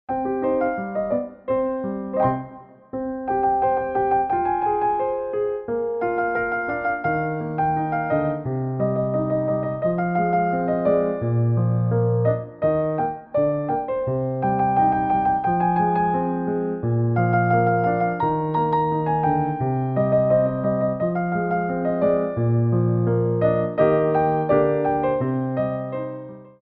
Medium Allegro 1
2/4 (16x8)